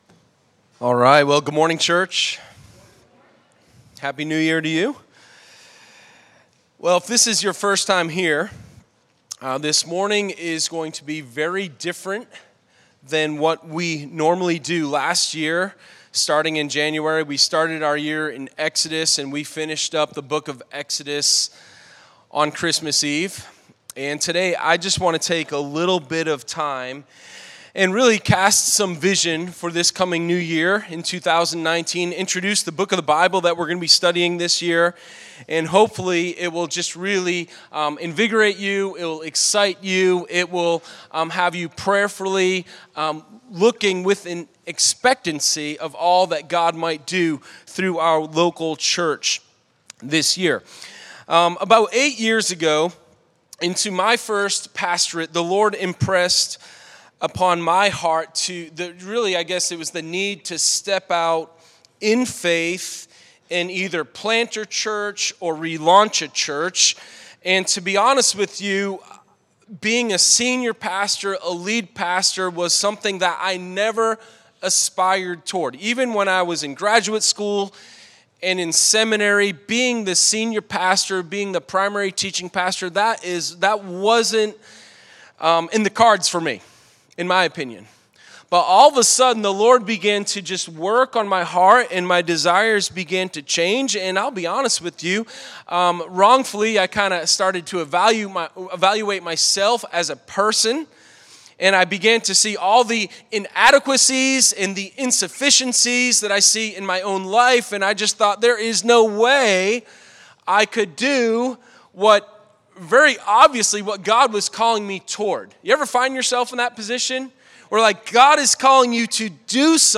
Sermon-0106_Vision-Sunday.mp3